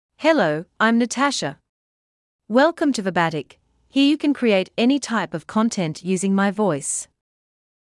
Natasha — Female English (Australia) AI Voice | TTS, Voice Cloning & Video | Verbatik AI
FemaleEnglish (Australia)
Natasha is a female AI voice for English (Australia).
Voice sample
Natasha delivers clear pronunciation with authentic Australia English intonation, making your content sound professionally produced.